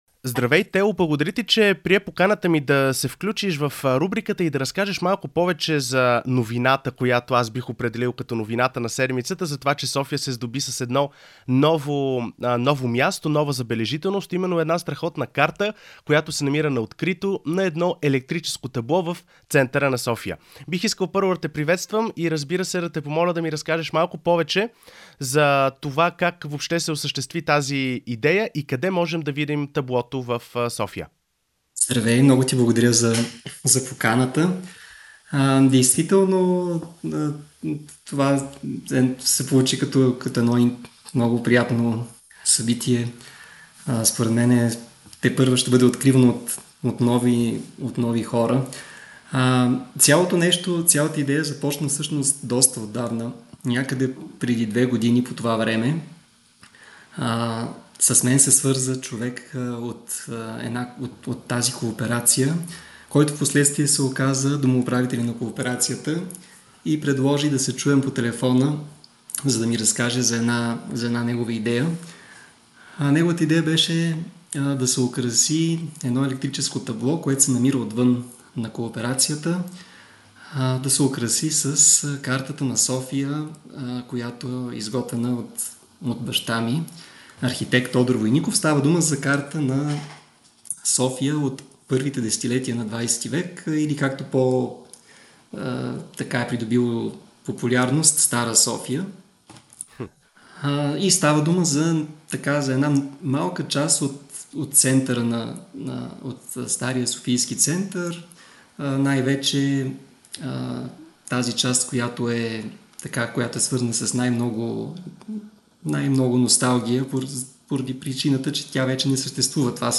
Всяка събота от 13 до 16 часа по Радио София